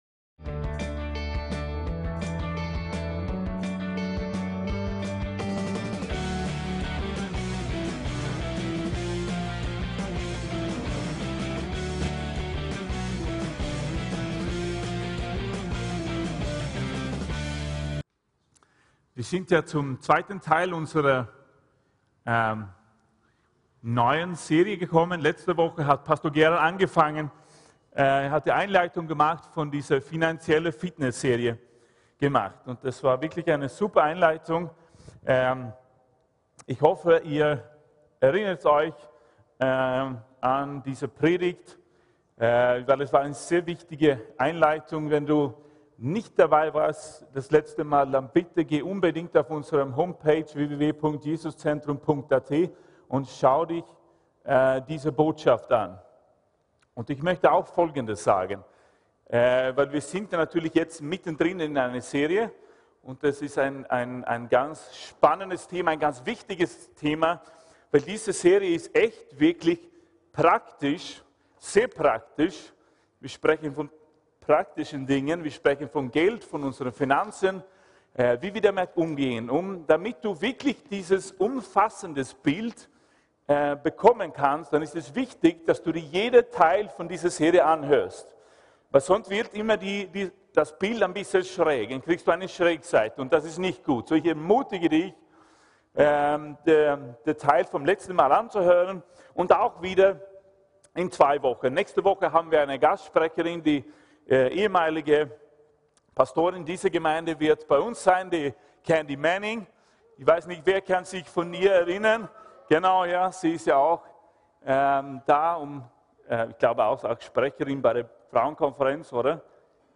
VCC JesusZentrum Gottesdienste